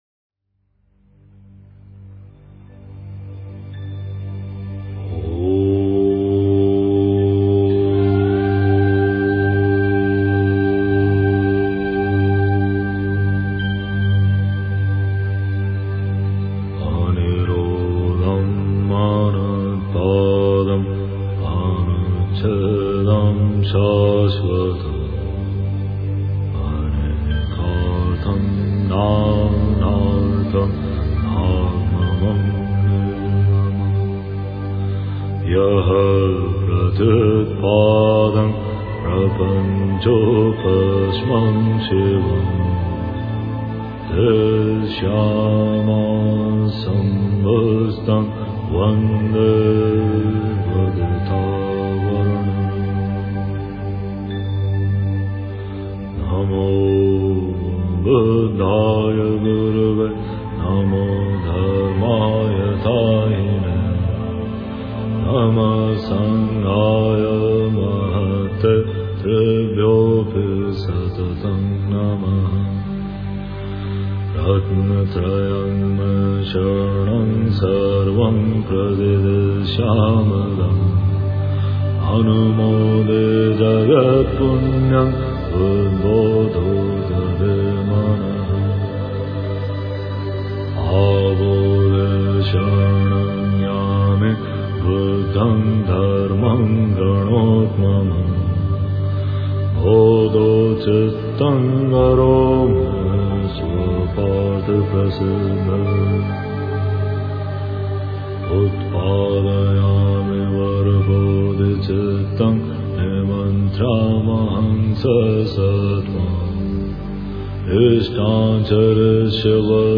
werden von stimmungsvollen Melodien getragen